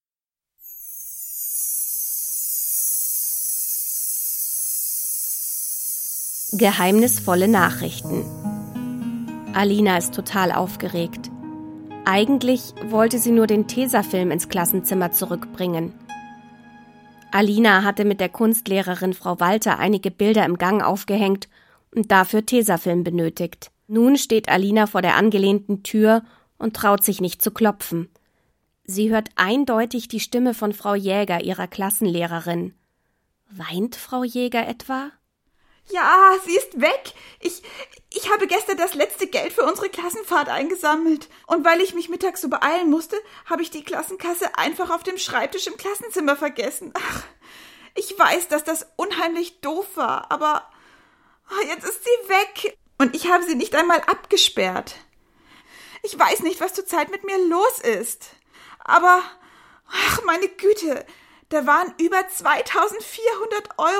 Kriminell gut hören, Klasse 3/4 Fesselnde Hörspielkrimis mit differenzierten Aufgaben zur Förderung der Hörkompetenz Sabine Reichel (Autor) Media-Kombination 80 Seiten | Ausstattung: Online Resource 2024 | 5.